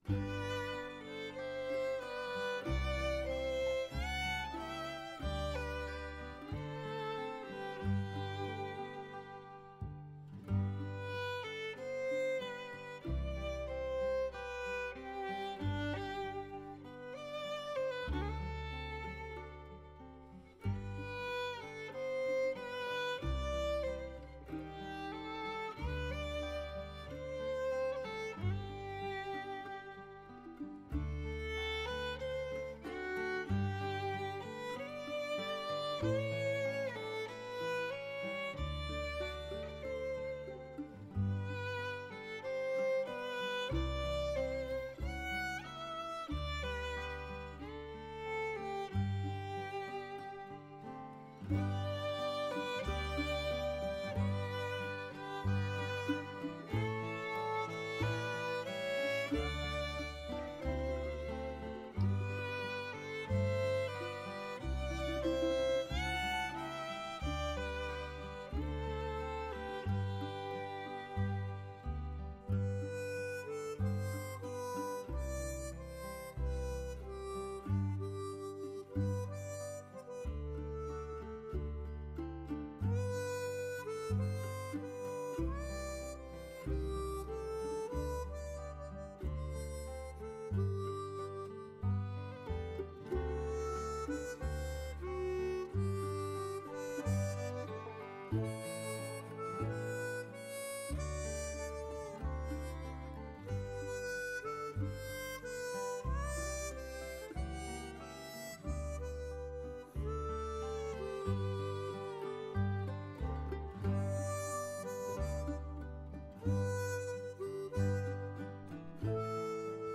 American Fiddler
violoniste américain